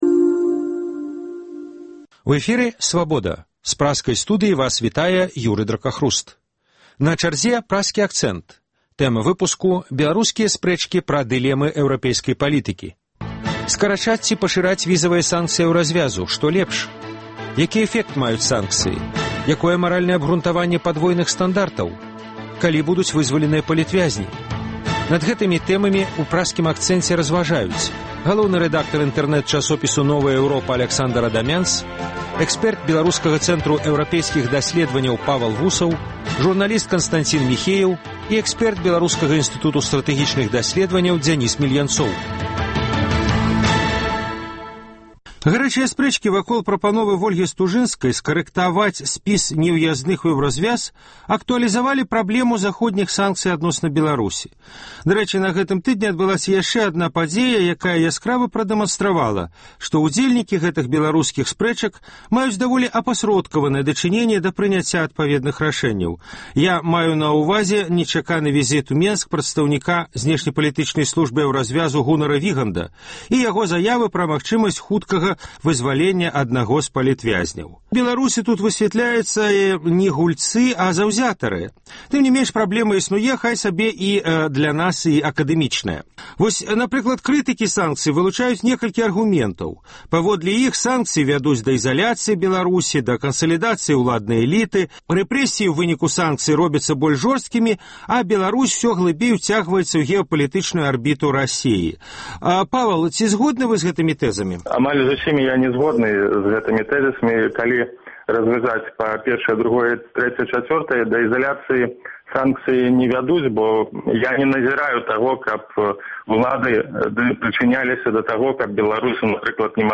Па гэтых тэмах у “Праскім акцэнце” вядуць спрэчку: